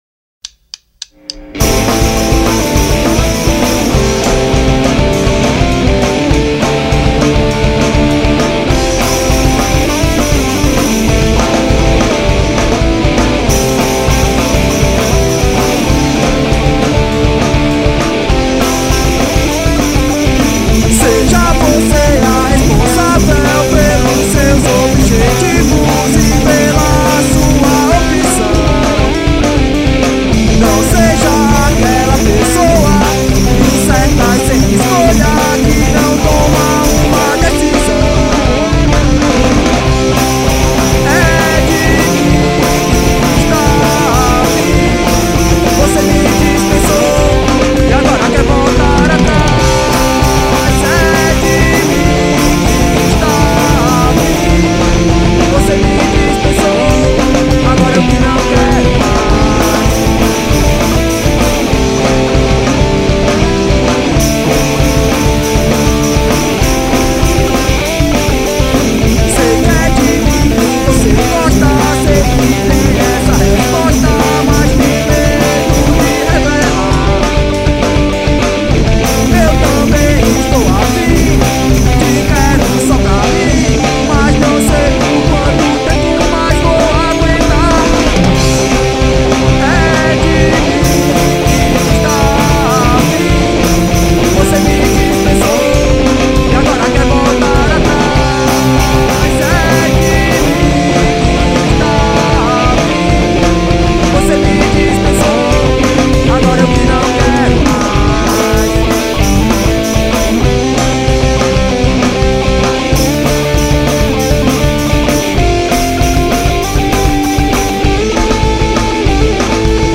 EstiloHardcore